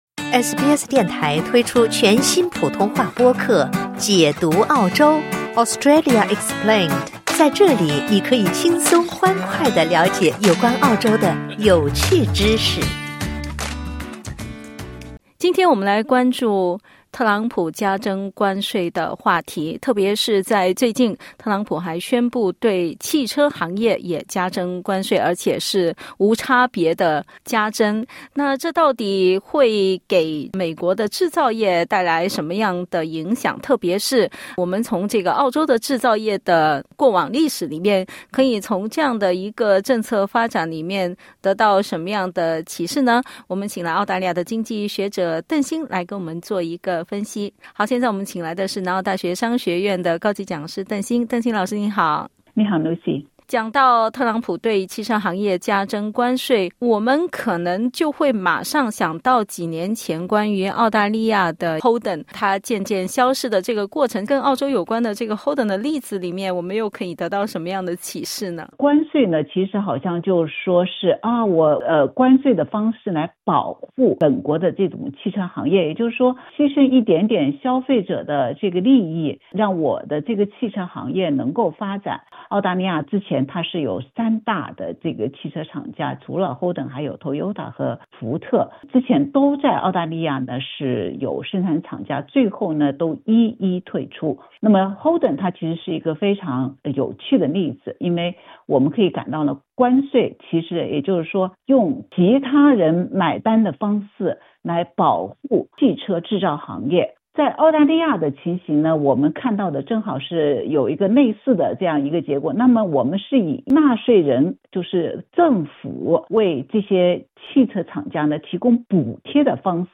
（点击音频收听详细采访） 美国总统唐纳德·特朗普(Donald Trump）宣布对所有进入美国的外国汽车和汽车零部件征收25%的关税后，汽车行业已成为全球贸易战的另一条战线。 下周(4月2日) 关税措施生效后，日本和欧洲汽车制造商将受到严重影响。